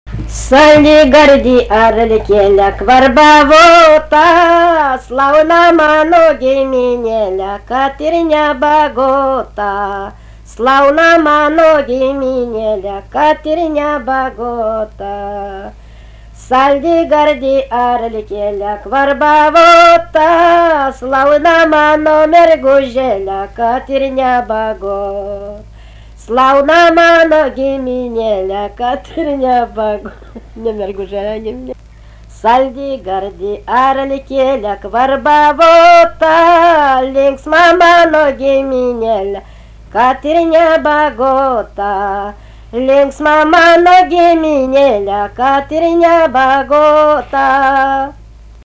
Aukštadvaris
vokalinis